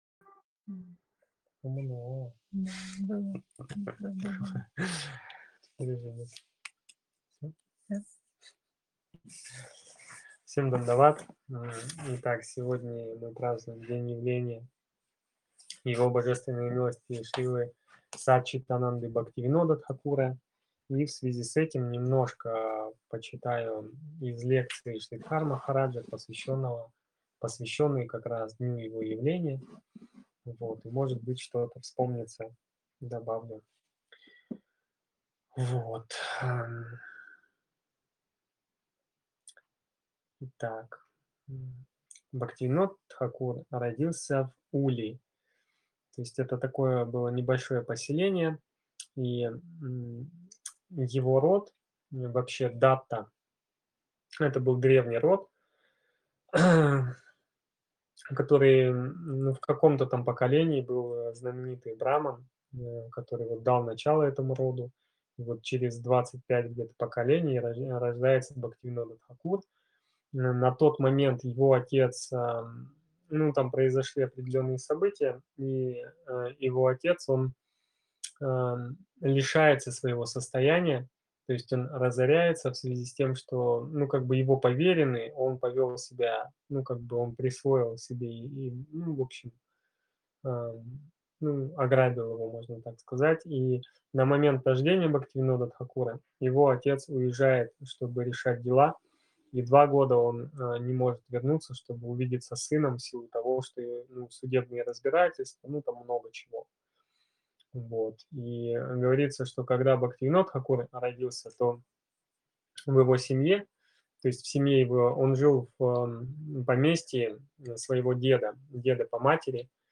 Место: Узунджа (Крым)
Лекции полностью